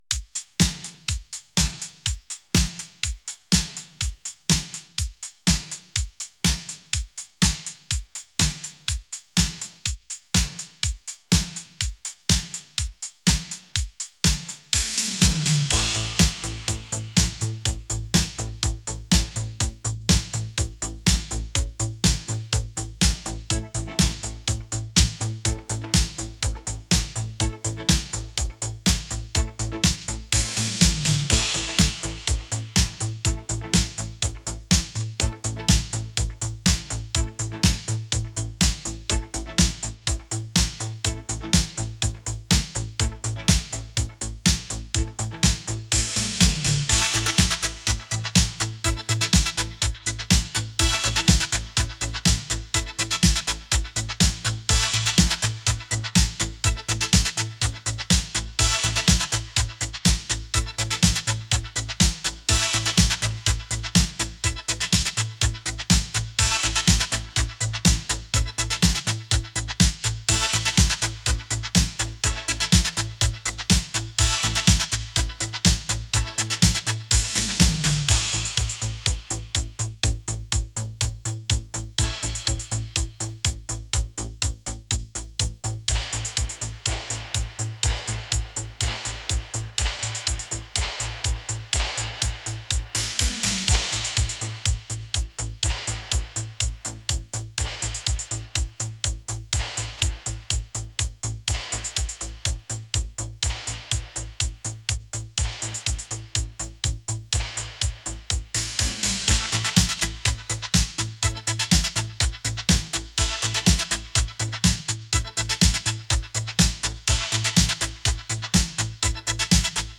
electronic